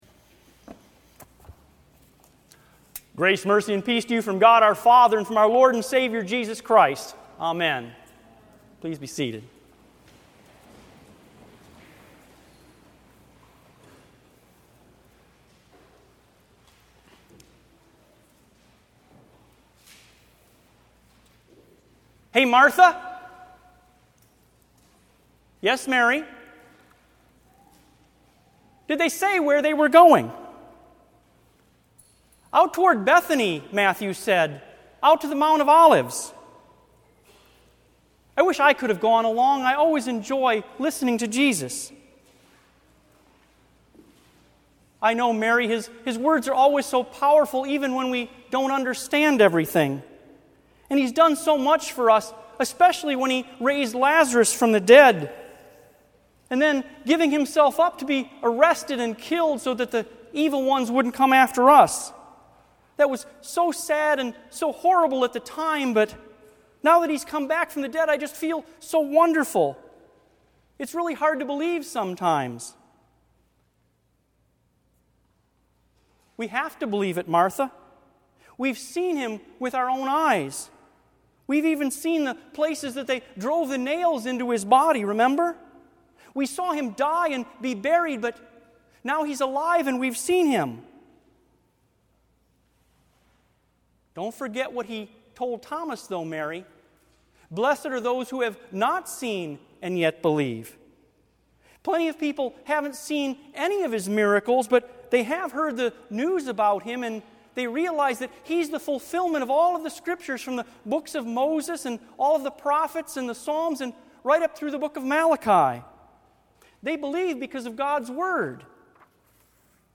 Note: The structure of the following sermon is that of a four dialogs between pairs of individuals as they each discuss the meaning of Jesus’ ministry, crucifixion, resurrection, and ascension.